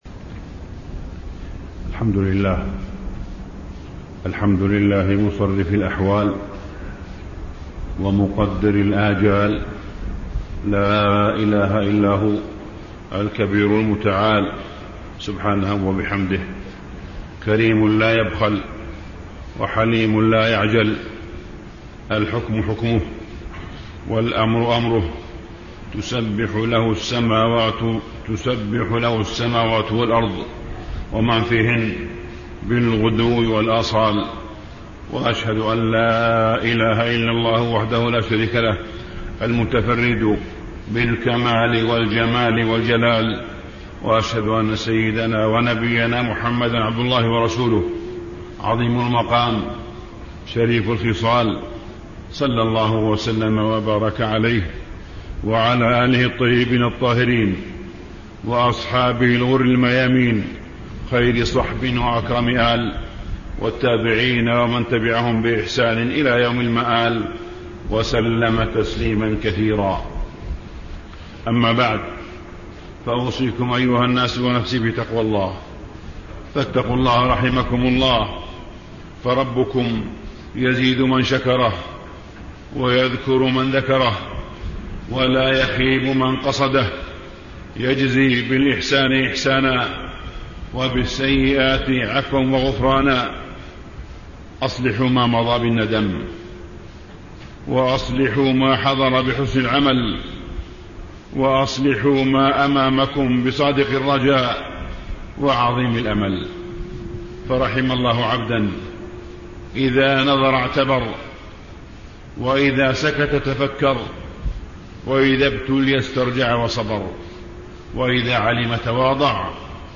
تاريخ النشر ١٧ صفر ١٤٣٥ هـ المكان: المسجد الحرام الشيخ: معالي الشيخ أ.د. صالح بن عبدالله بن حميد معالي الشيخ أ.د. صالح بن عبدالله بن حميد الشتاء آداب وأحكام